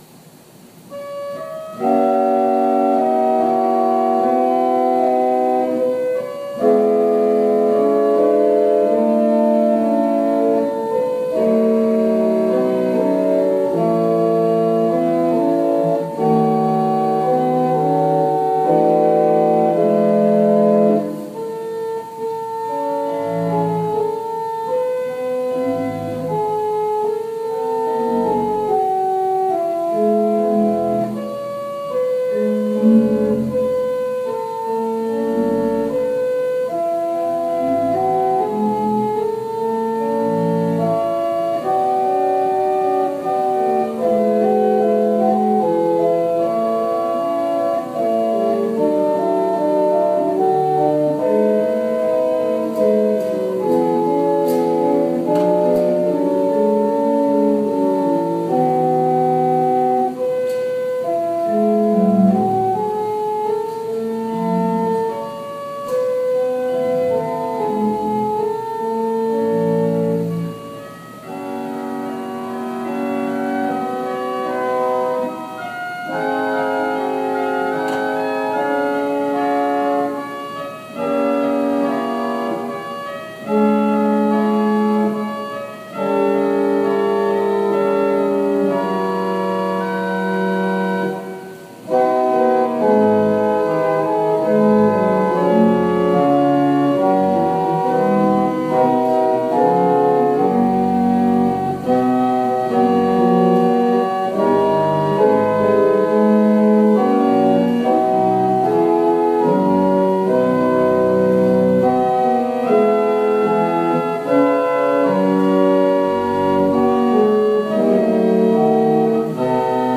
Worship Service December 20, 2020 | First Baptist Church, Malden, Massachusetts
Call to Worship / Invocation / Lord’s Prayer